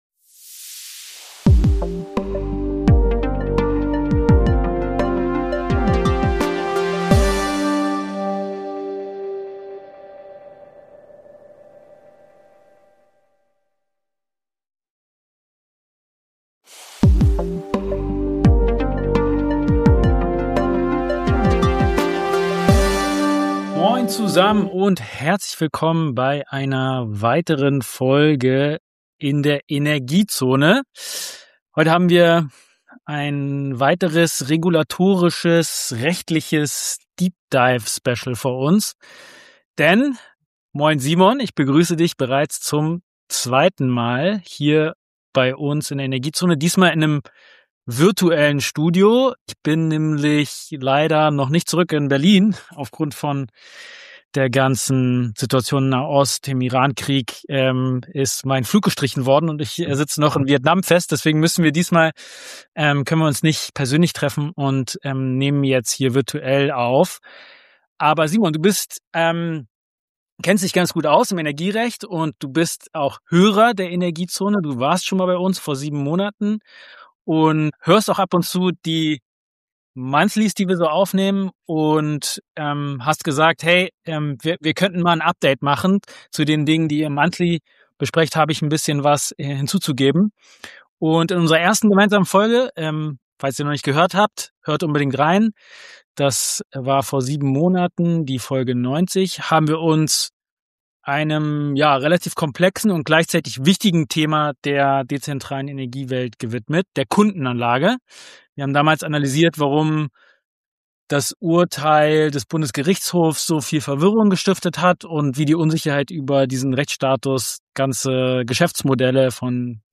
Aufgrund unvorhergesehener Umstände nehmen wir die Folge virtuell auf, was jedoch nicht davon ablenkt, dass der Austausch über die komplexen rechtlichen Rahmenbedingungen umso wertvoller ist.